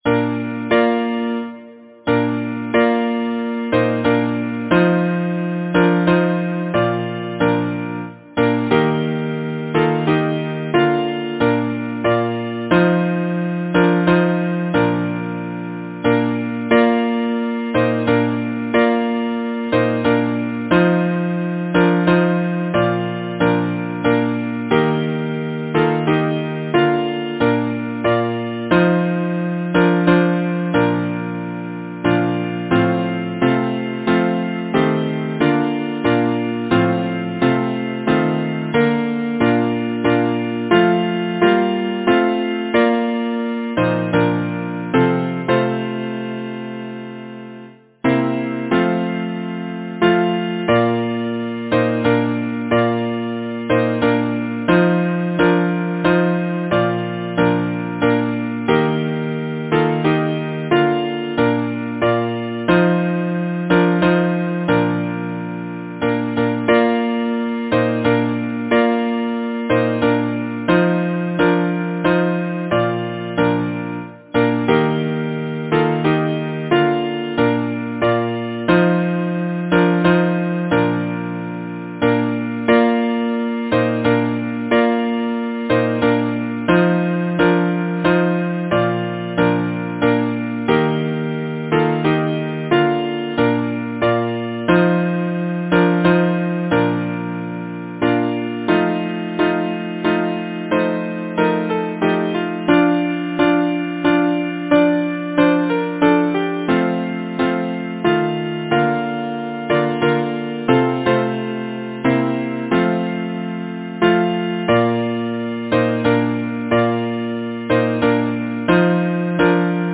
Title: Araby’s Daughter Composer: George Frederick Kiallmark Lyricist: Thomas Moore Number of voices: 4vv Voicing: SATB Genre: Secular, Partsong
Language: English Instruments: A cappella